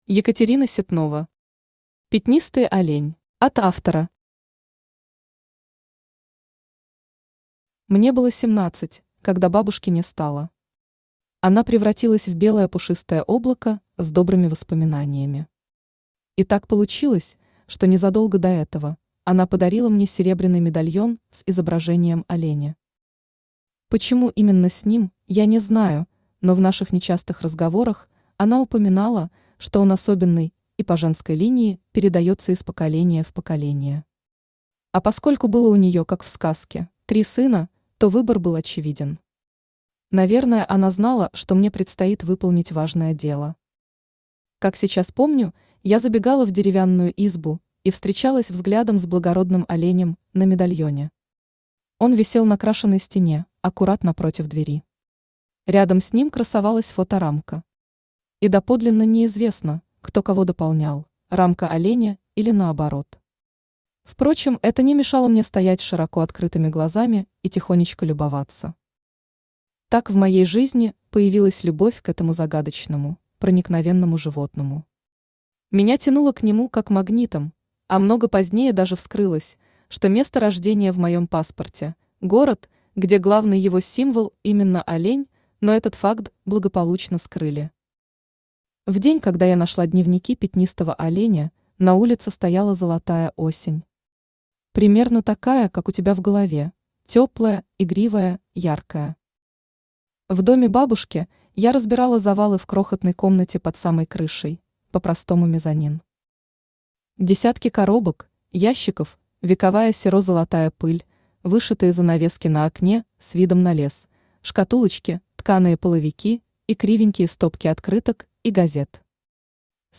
Аудиокнига Пятнистый Олень | Библиотека аудиокниг
Aудиокнига Пятнистый Олень Автор Екатерина Ситнова Читает аудиокнигу Авточтец ЛитРес.